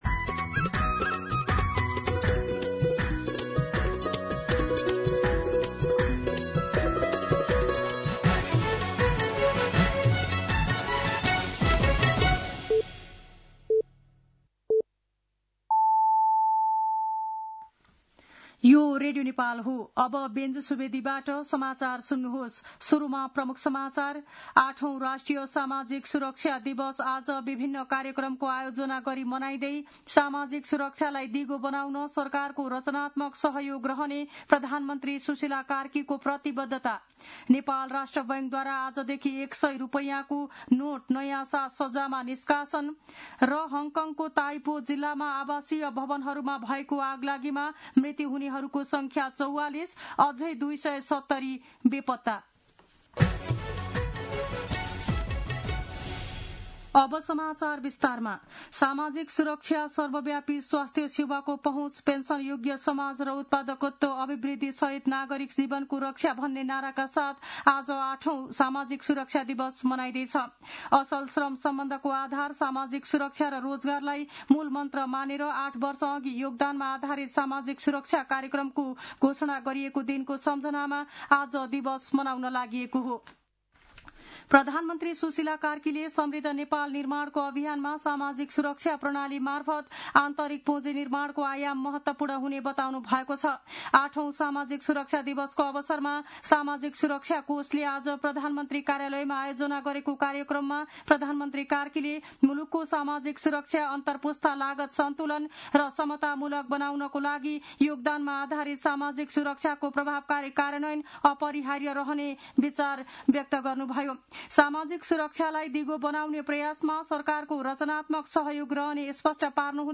दिउँसो ३ बजेको नेपाली समाचार : ११ मंसिर , २०८२
3-pm-news-8-11.mp3